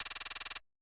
normal-sliderslide.wav